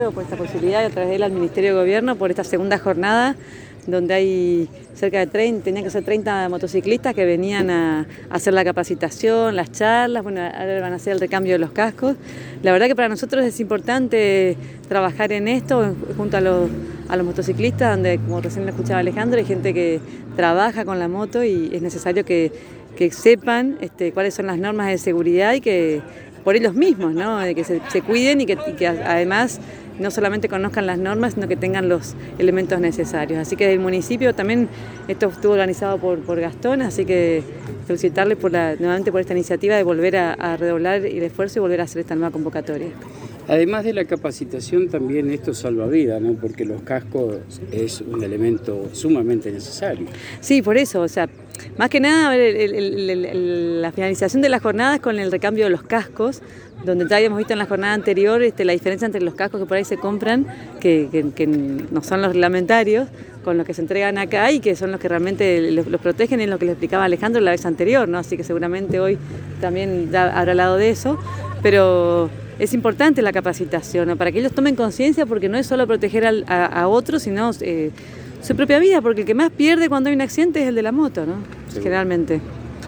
El Secretario de Gobierno Gastón Casares en diálogo exclusivo con la ANG informó sobre la jornada y además resaltó que desde el comienzo de la gestión de María Eugenia Safrán se trabaja fuertemente por la seguridad vial y las estadísticas están mostrando el fruto de este arduo trabajo.